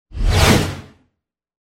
Звуки движения, переходов
Резкое движение